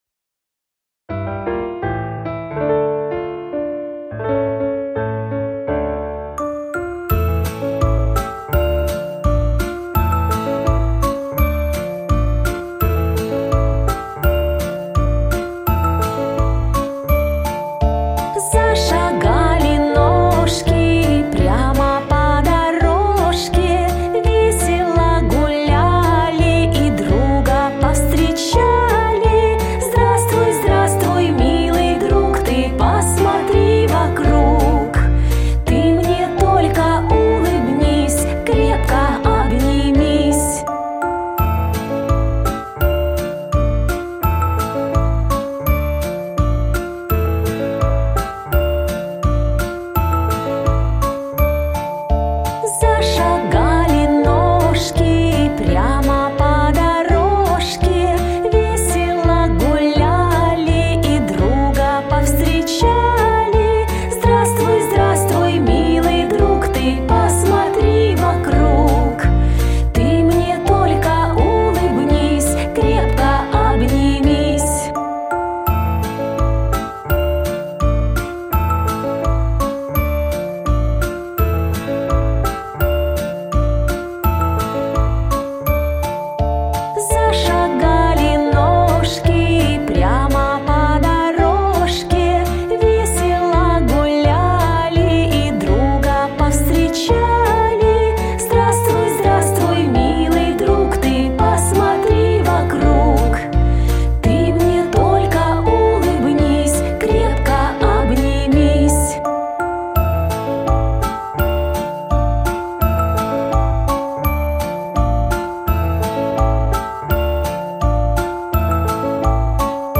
• Категория: Детские песни
Музыкальная игра